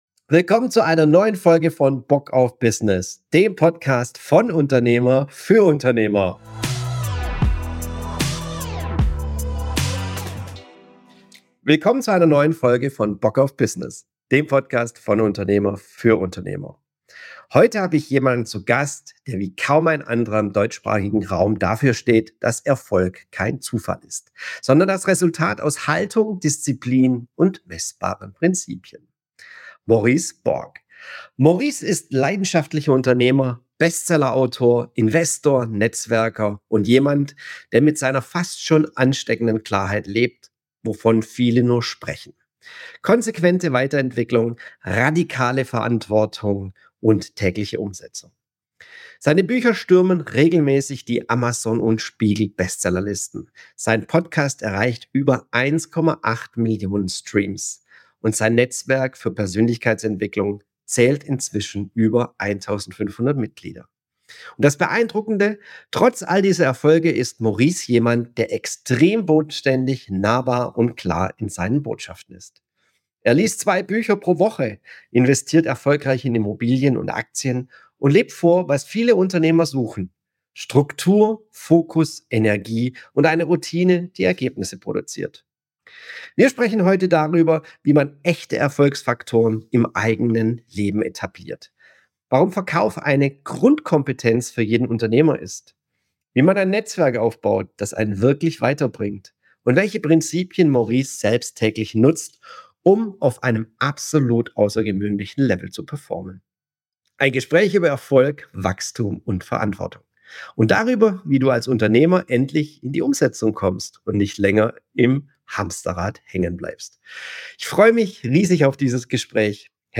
Dieses Gespräch ist ein ehrlicher Blick darauf, wie unternehmerischer Erfolg wirklich entsteht – von innen nach außen.